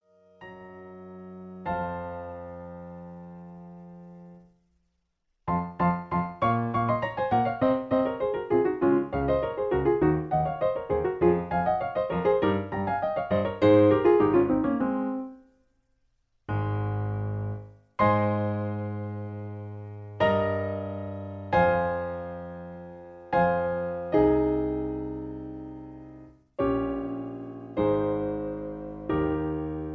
In C sharp. Piano Accompaniment